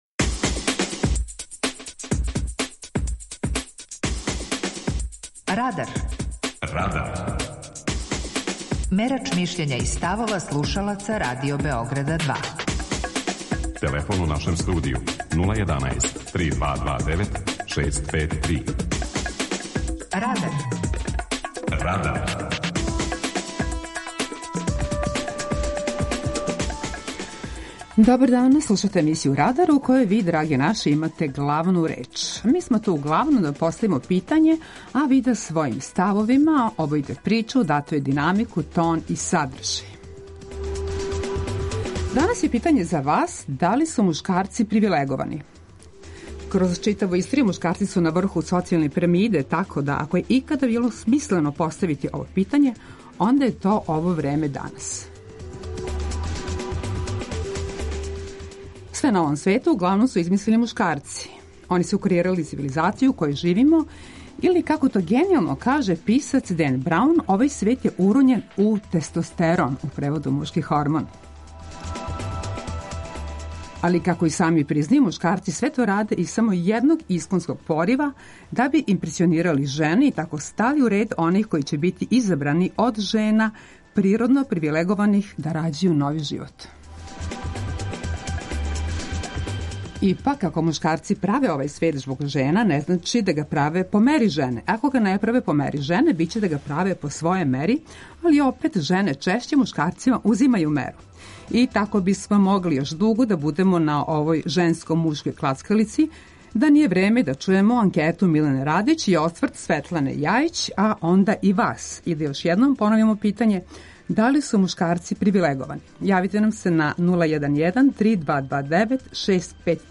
Данашње питање за наше слушаоце је: Да ли су мушкарци привилеговани? преузми : 18.71 MB Радар Autor: Група аутора У емисији „Радар", гости и слушаоци разговарају о актуелним темама из друштвеног и културног живота.